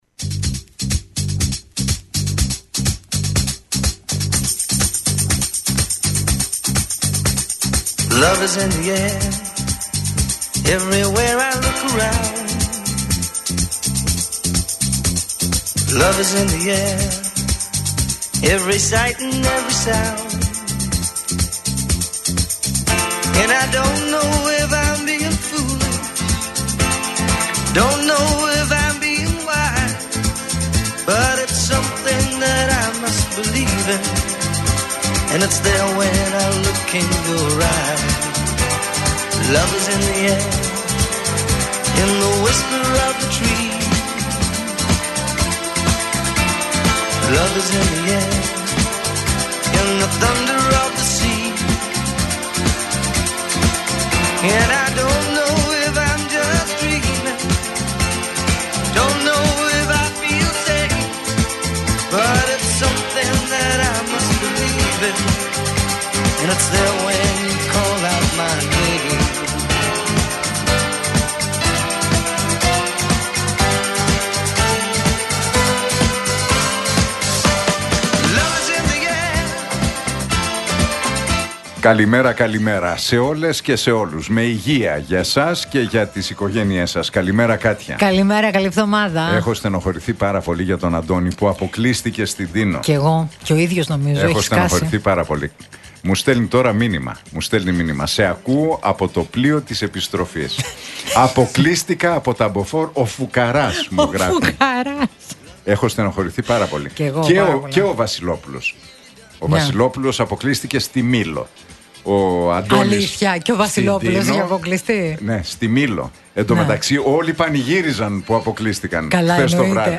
Ακούστε την εκπομπή του Νίκου Χατζηνικολάου στον ραδιοφωνικό σταθμό RealFm 97,8, την Δευτέρα 30 Ιουνίου 2025.